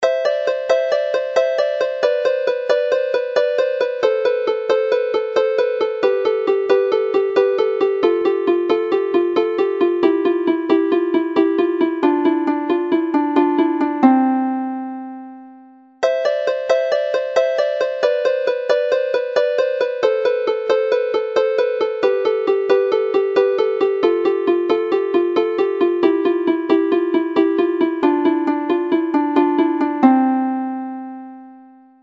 This month, Cynghansail is set in the key of C and is well suited to the harp, flute and whistle.